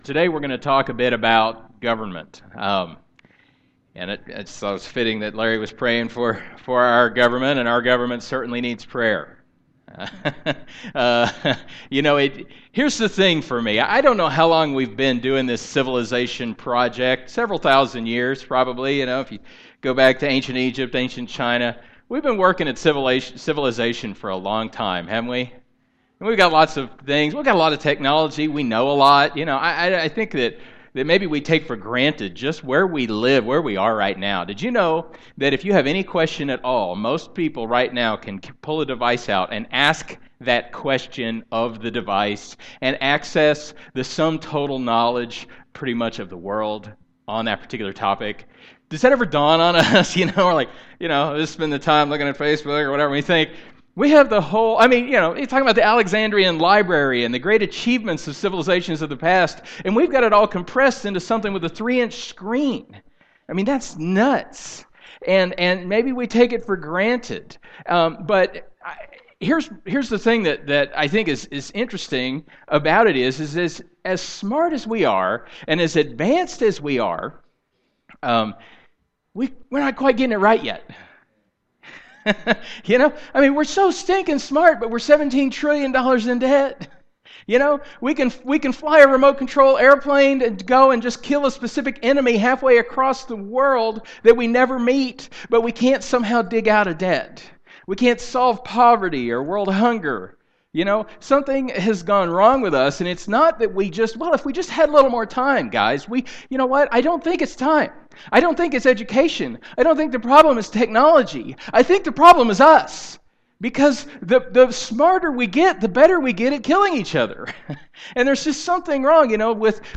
Here is lesson #29 in the Messiah Series.